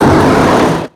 Cri de Métang dans Pokémon X et Y.